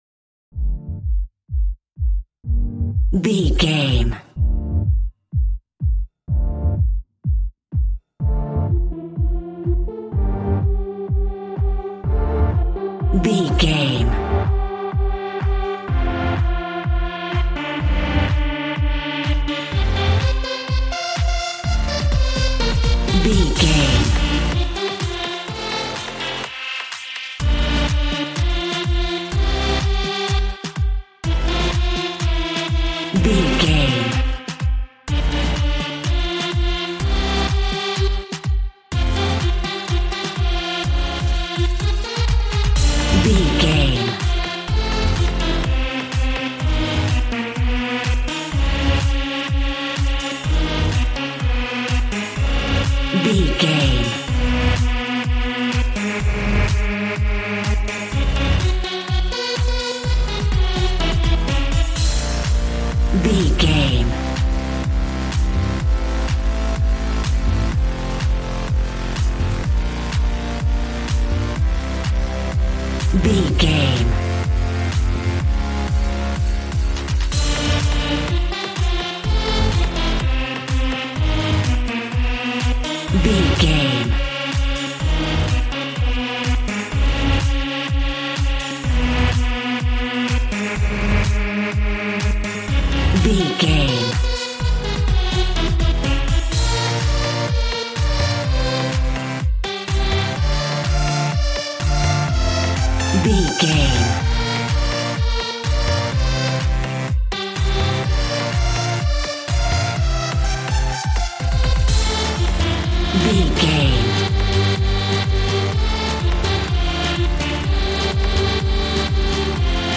Aeolian/Minor
Fast
groovy
energetic
fun
synthesiser
drums